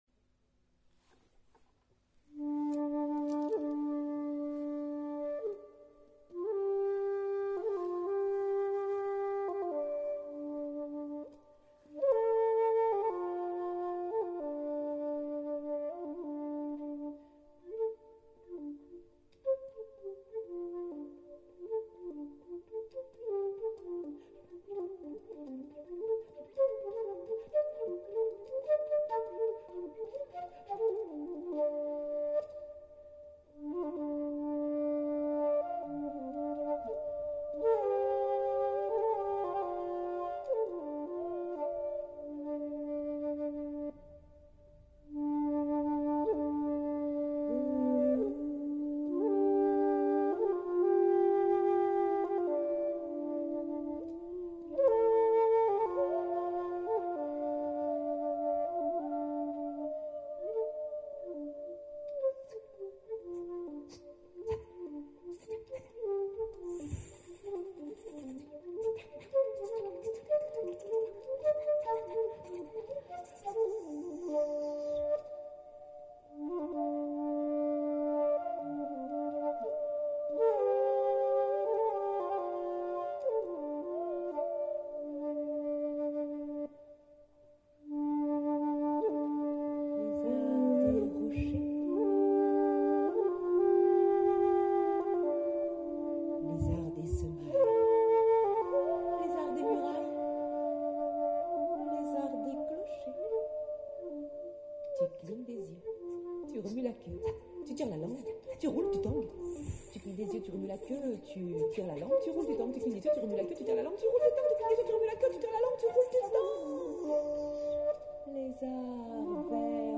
Création autour de la parole et de sa musique,
des ambiances sonores du jardin réel
La musique se créée, se transforme habille et sculpte la parole.
Musique instrumentale – flûte traversière, vocale, d’ambiances sonores,
d’objets détournés – végétaux, pots de fleurs, fontaine…
Tout ceci est mis en boucle et orchestré en direct